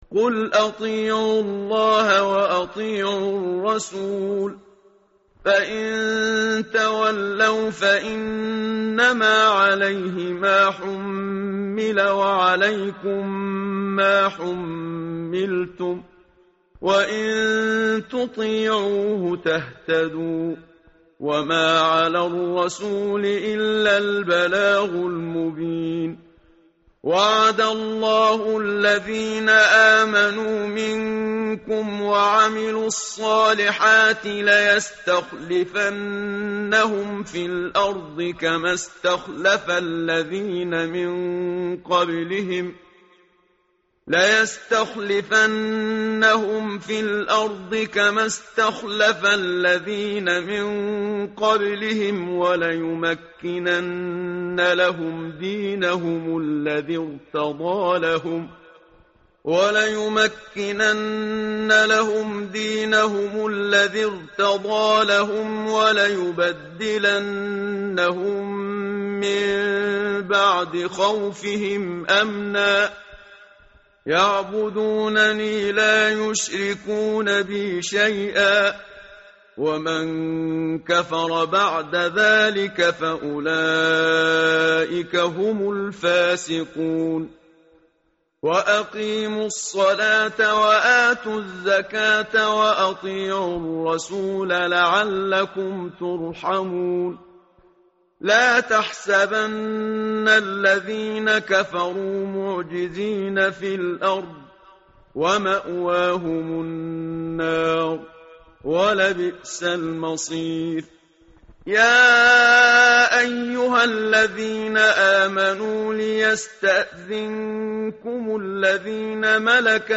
متن قرآن همراه باتلاوت قرآن و ترجمه
tartil_menshavi_page_357.mp3